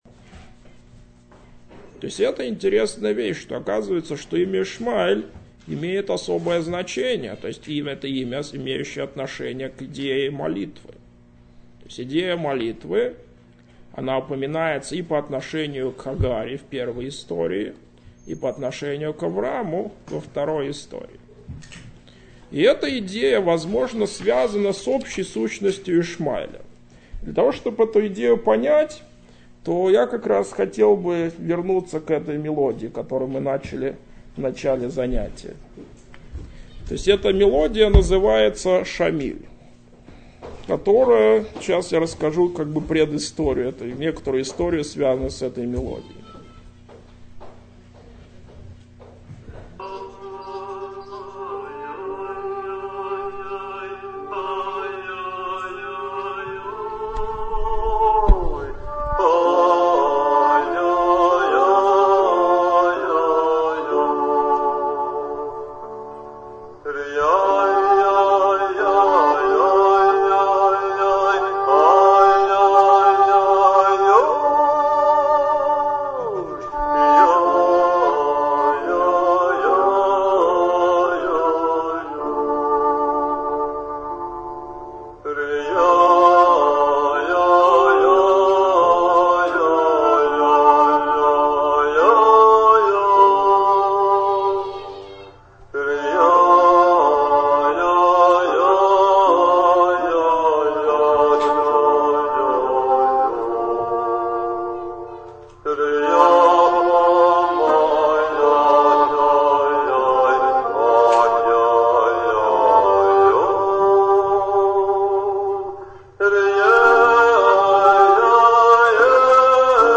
Нигун “Шамиль” комментарий